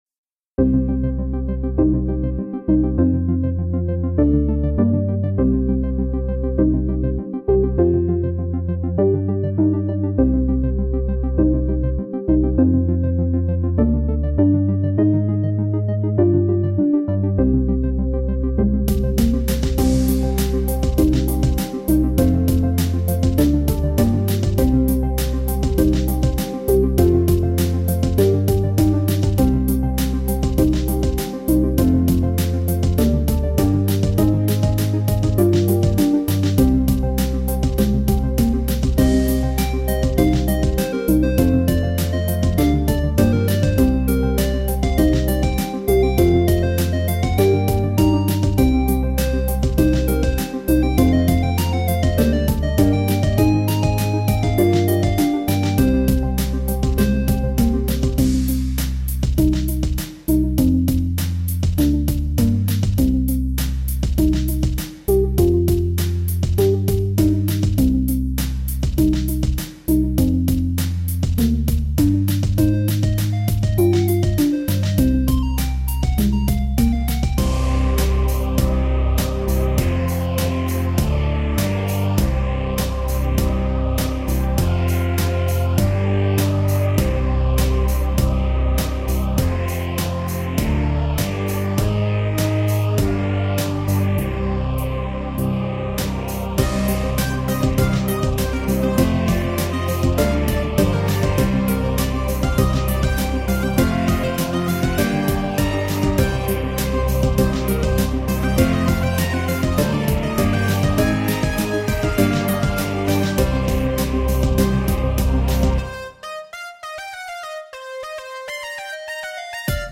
But anyway, enjoy my attempt some electronic weird stuff.